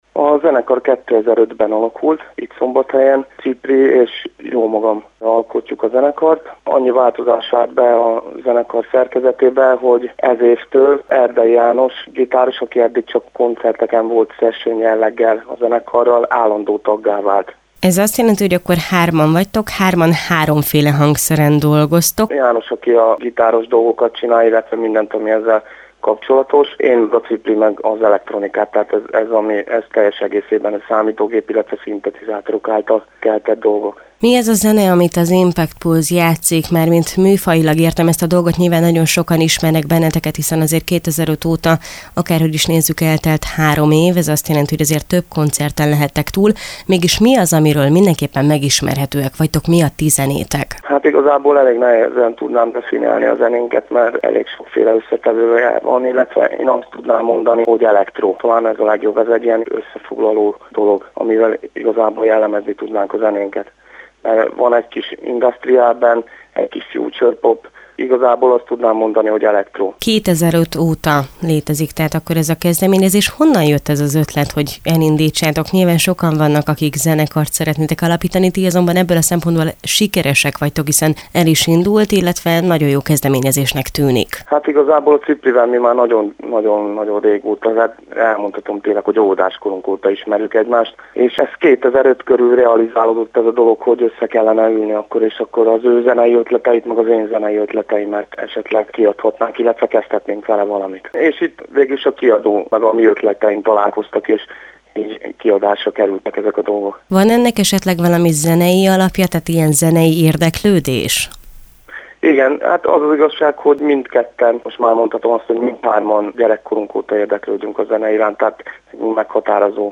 Rádióinterjú: